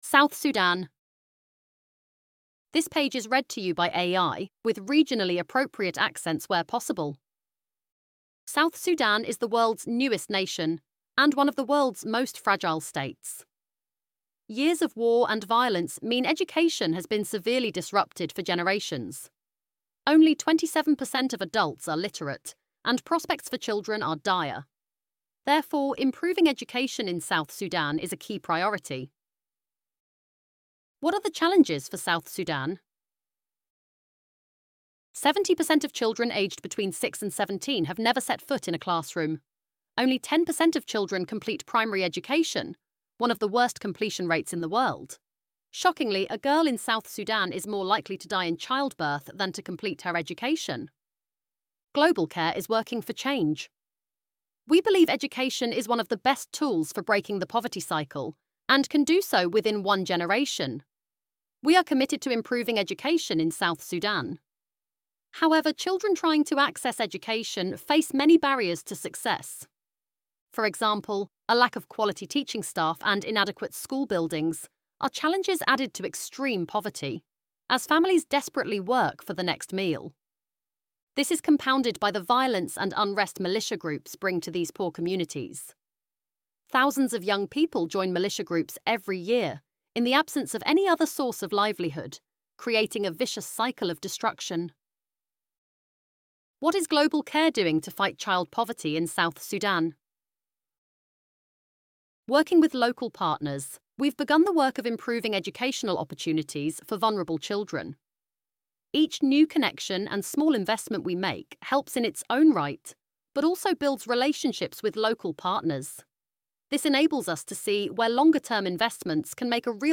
ElevenLabs_south-sudan.mp3